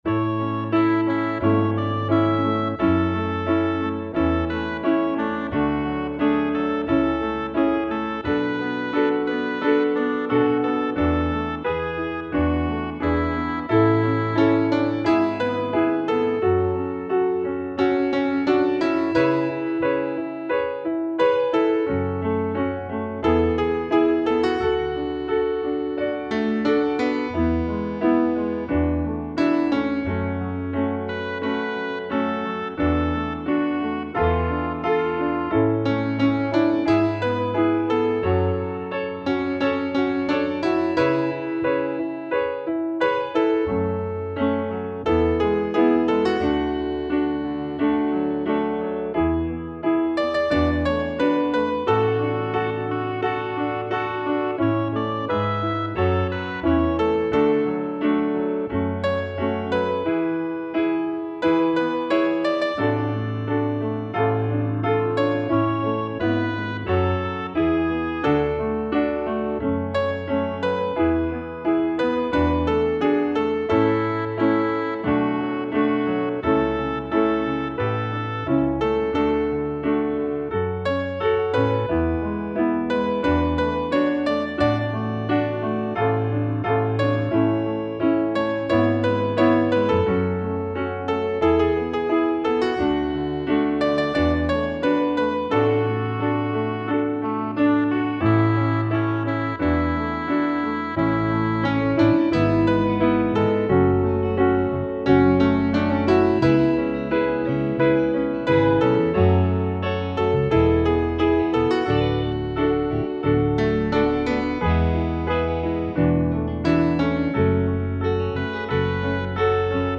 lovely ballad